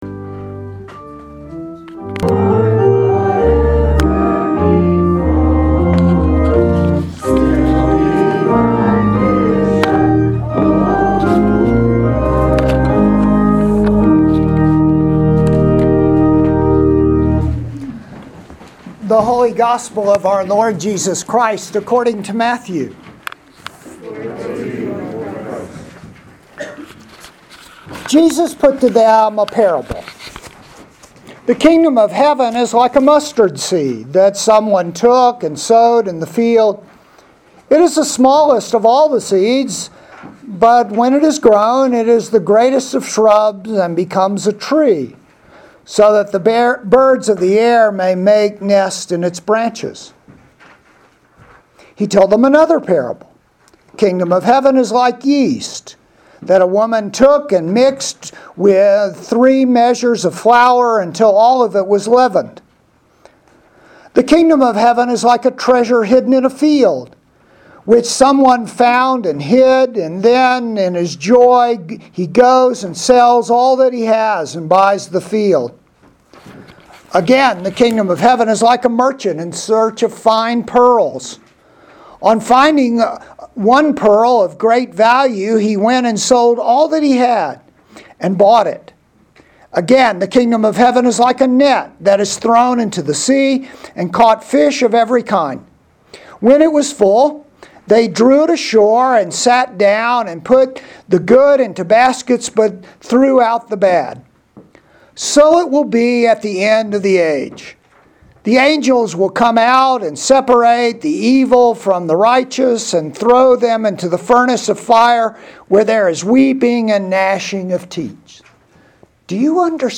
Sermons
St. John's Episcopal Church Chapel of the Transfiguration